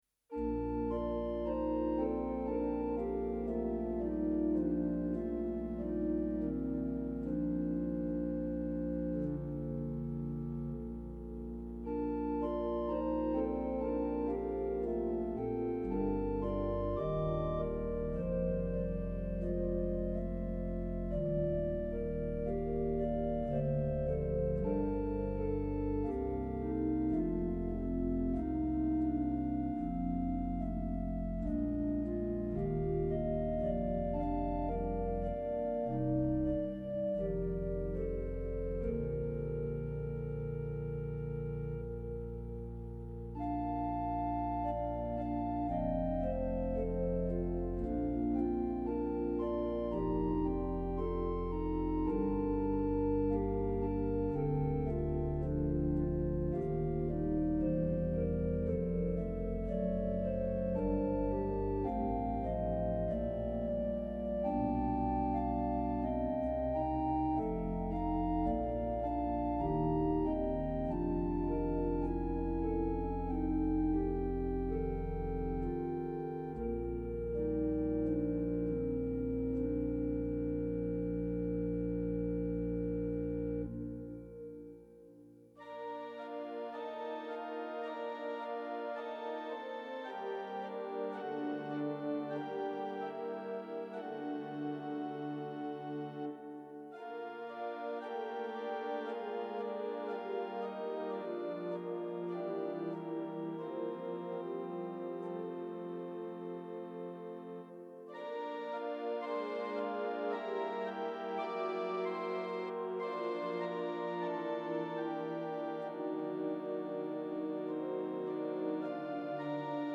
Organ Interludes Audio Gallery